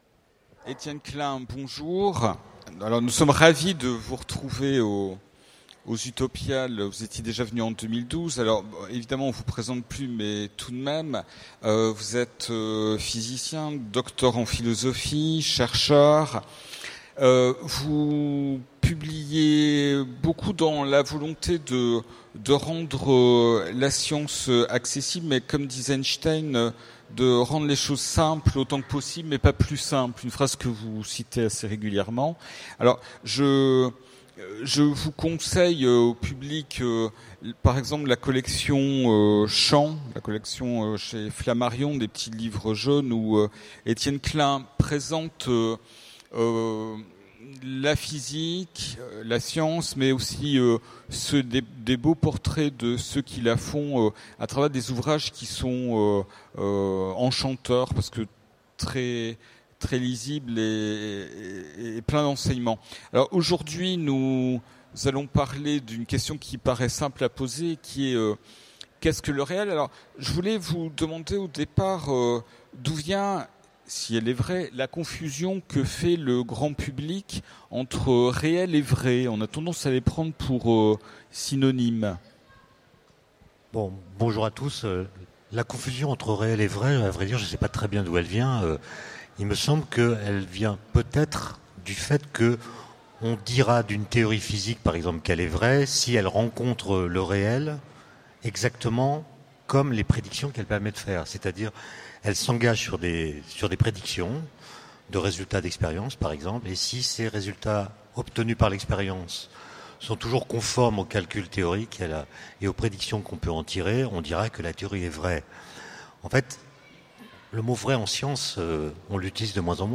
- le 31/10/2017 Partager Commenter Utopiales 2015 : Rencontre avec Étienne Klein Télécharger le MP3 à lire aussi Étienne Klein Genres / Mots-clés Rencontre avec un auteur Conférence Partager cet article